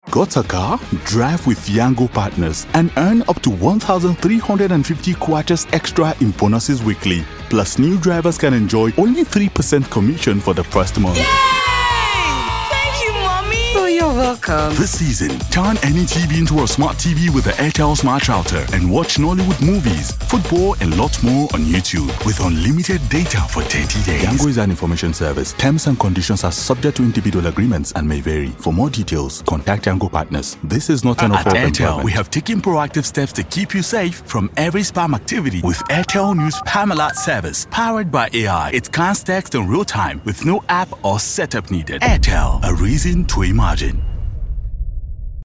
a deep, warm baritone voice with authentic African and Nigerian accents
Radio Imaging
I specialize in recording voiceovers with authentic African accents, including West African and Sub saharan African accent.
Fully soundproofed booth
BaritoneBassDeep